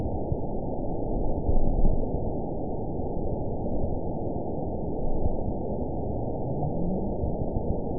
event 919789 date 01/23/24 time 22:44:46 GMT (1 year, 3 months ago) score 9.52 location TSS-AB03 detected by nrw target species NRW annotations +NRW Spectrogram: Frequency (kHz) vs. Time (s) audio not available .wav